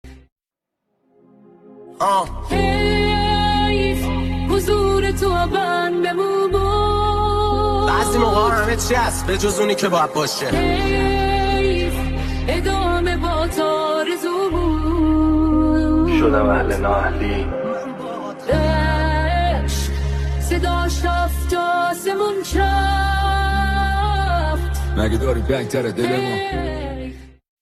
+ ریمیکس ترند شده اینستا